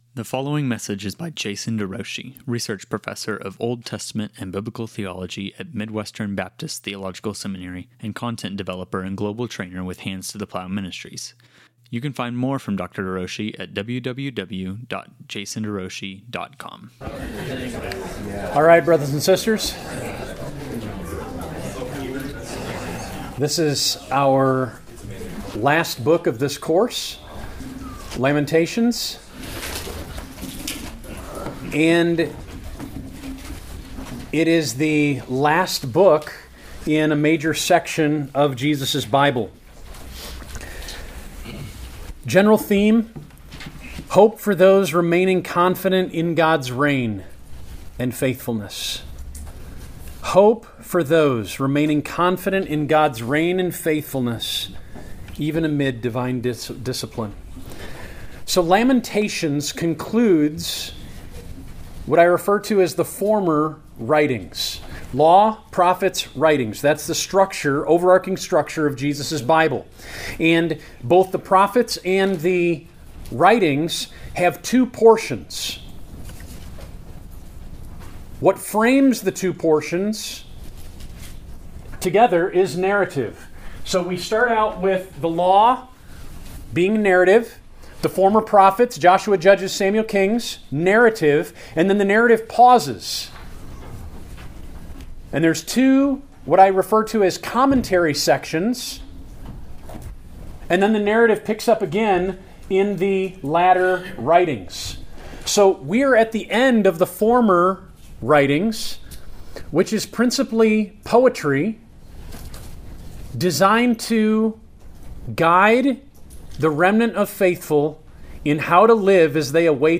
Lectures on Lamentations
Lamentations-Lecture.mp3